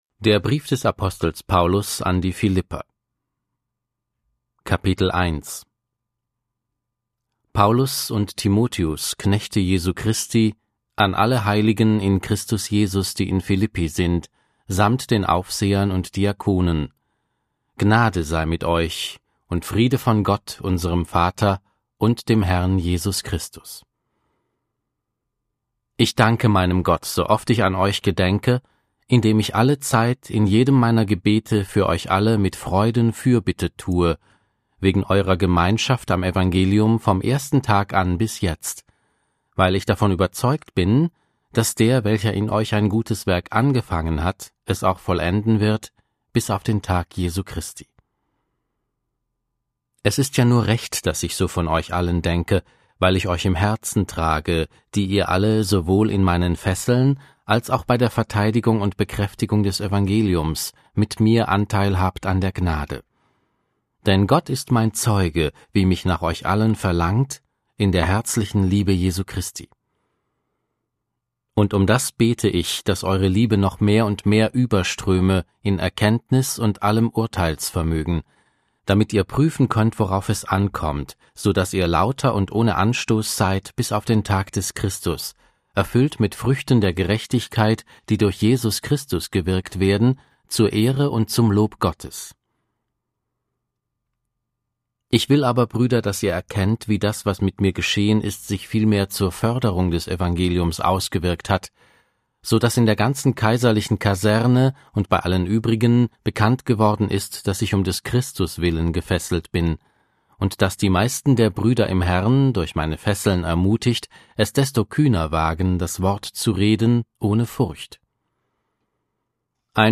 Audio Bibel - Schlachter 2000